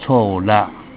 : 最近和朋友言談互動之中 : 才發現 桃園觀音的草缧 台語是唸 ㄘㄚ ㄌㄚ˙ 一般的注音符號無法準確拼讀台語 在這邊如果會使用教羅/台羅拼音會比較好 不過這個地名是從客語來的 正確寫法是「草漯」，三點水不是糸字邊 客語拼音coˋ labˋ